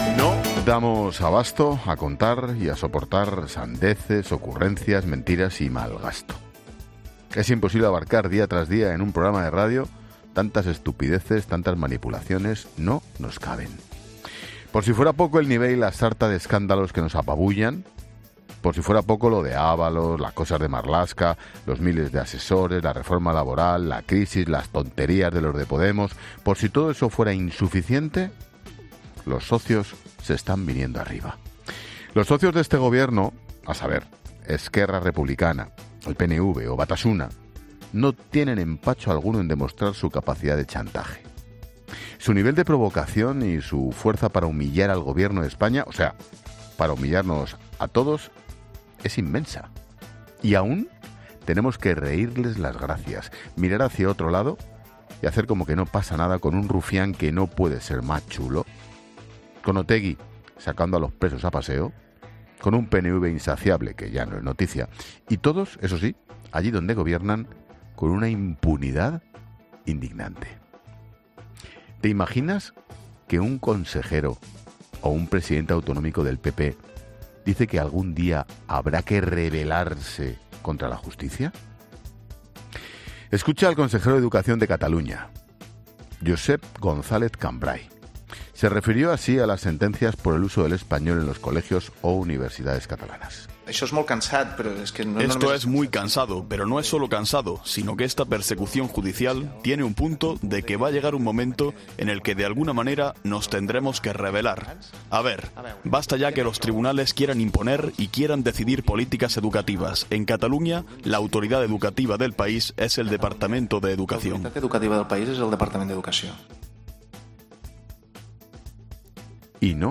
AUDIO: El director de 'La Linterna', Ángel Expósito, habla de los socios de Pedro Sánchez
Monólogo de Expósito